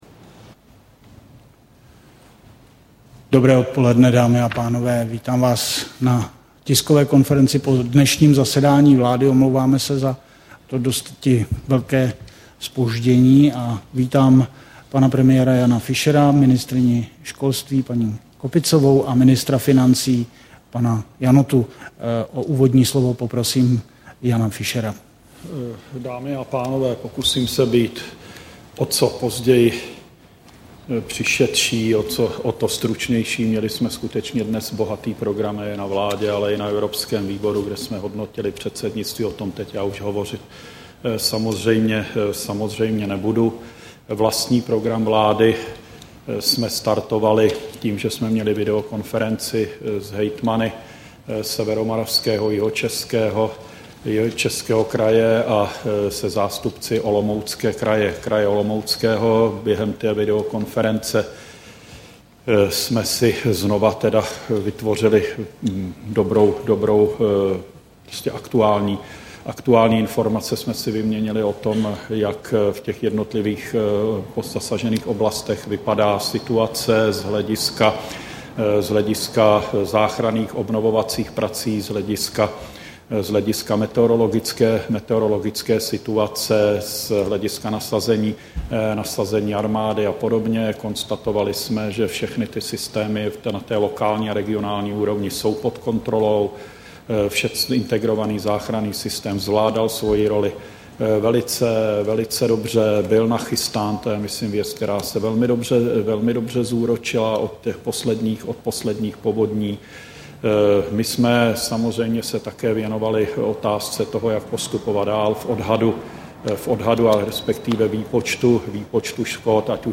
Tisková konference po zasedání vlády, 29. června 2009